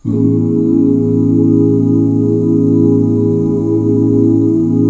AMAJ7 OOO -R.wav